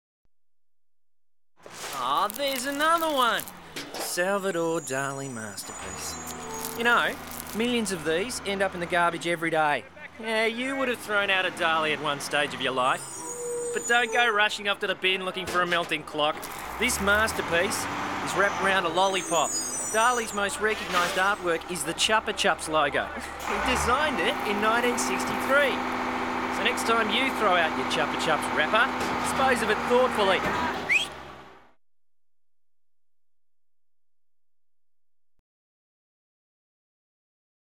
The radio ad was pulled off the air after one day for obvious reasons.
Chupa+Chups+radio+30.m4a